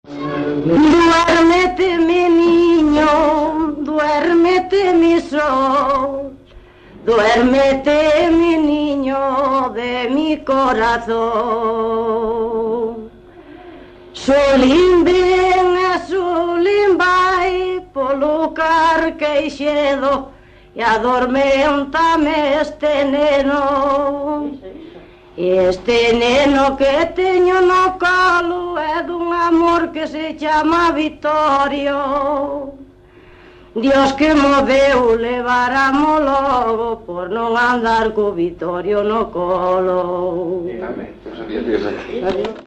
Palabras chave: arrolo canto de berce colo
Tipo de rexistro: Musical
Lugar de compilación: Fonsagrada, A
Soporte orixinal: Casete
Instrumentación: Voz
Instrumentos: Voz feminina